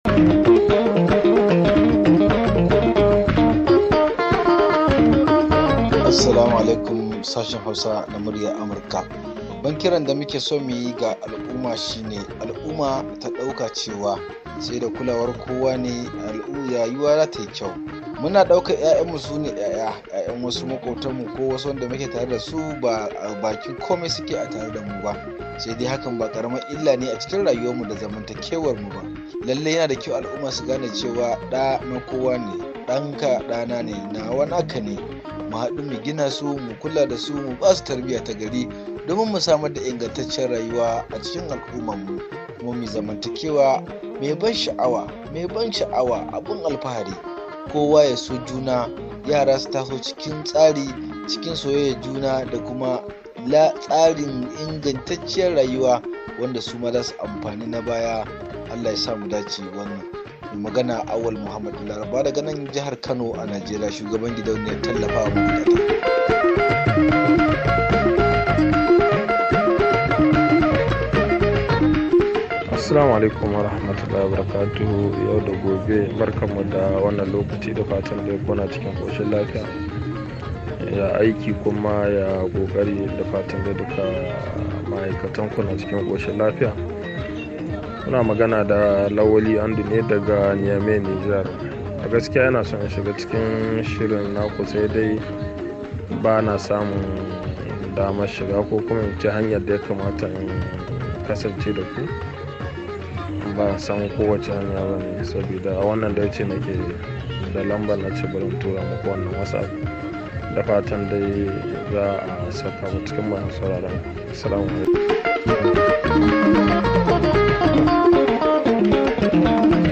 Sakonnin Muryoyin Masu Sauraro Daga WhatsApp